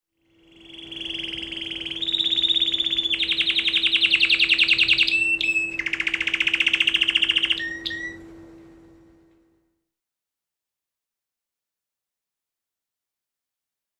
kanaria.wav